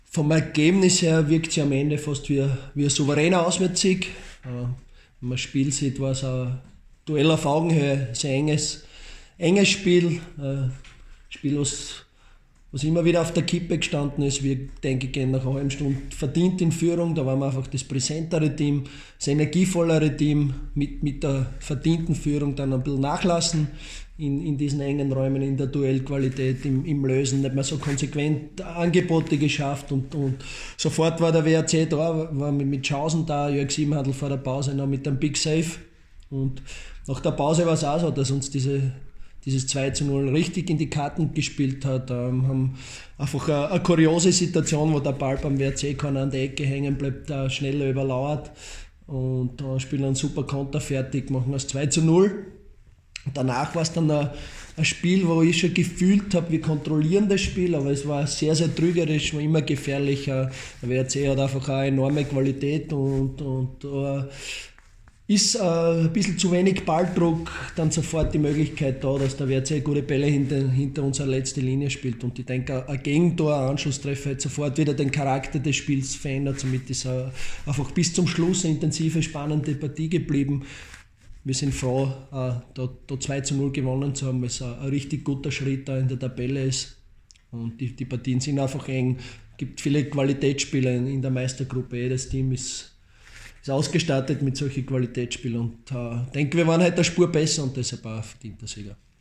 Stimmen: Wolfsberger AC vs. SK Sturm Graz